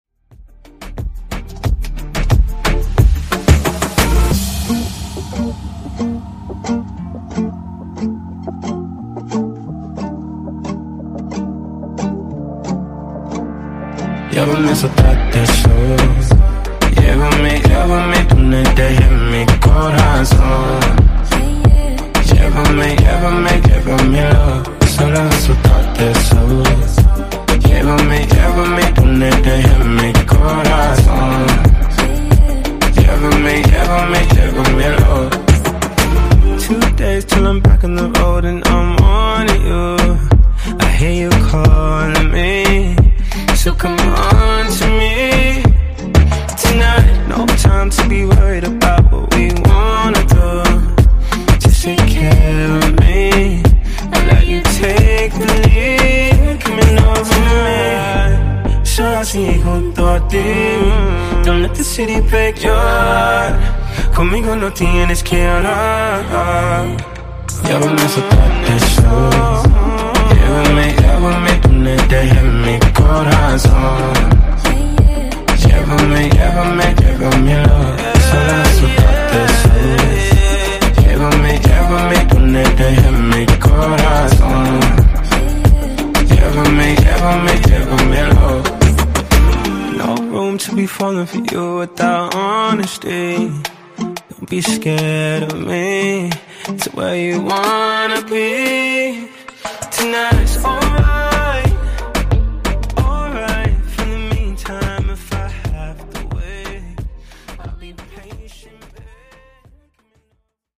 Genre: RE-DRUM
Clean BPM: 130 Time